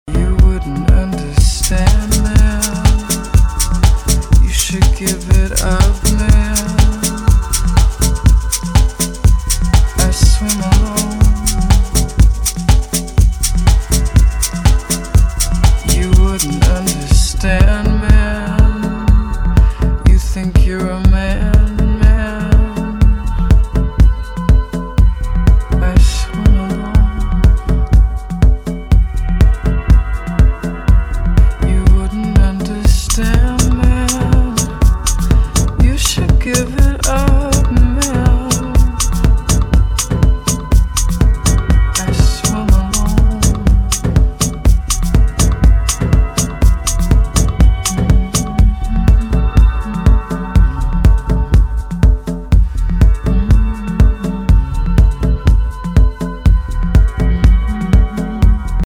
amps up the percussion to create a hypnotic club jam